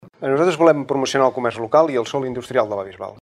Debat Electoral la Bisbal 2019
Com no podia ser d’altra manera Ràdio Capital ha emès el col·loqui.